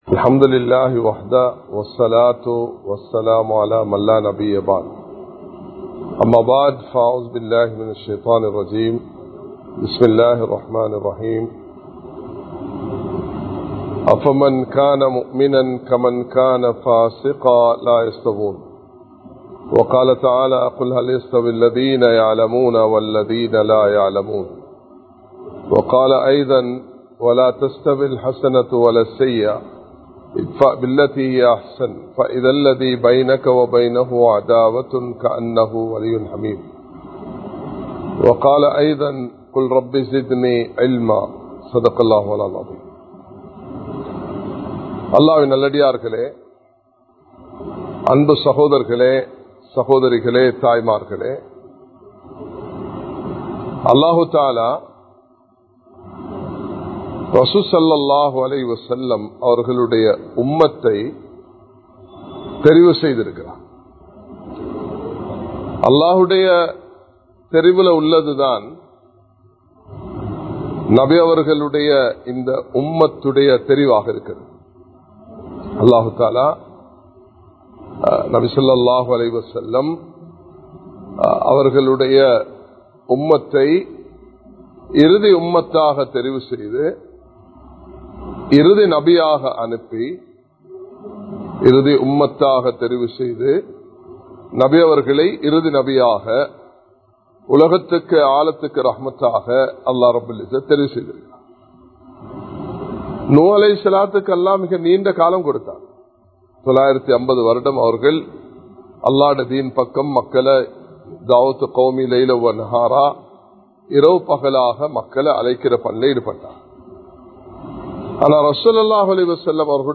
சிறந்த சமூகத்தின் 04 பண்புகள் | Audio Bayans | All Ceylon Muslim Youth Community | Addalaichenai
Live Stream